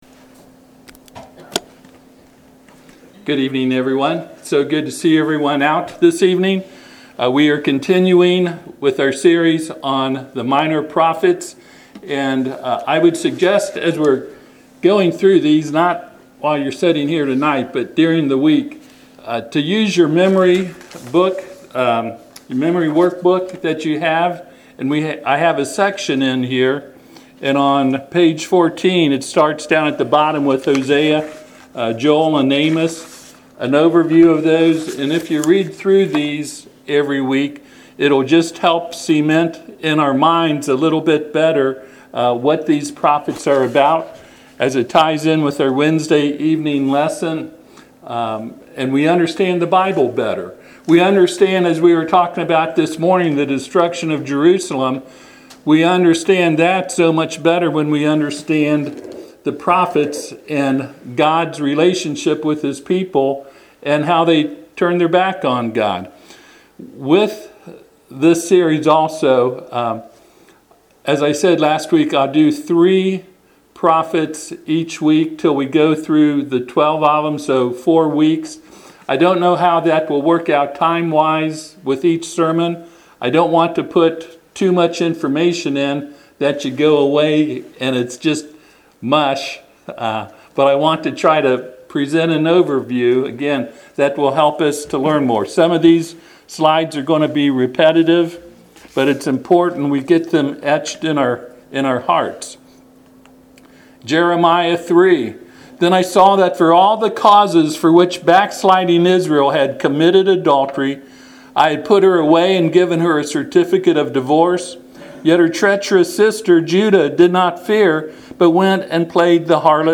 Passage: Hosea 2:14 Service Type: Sunday PM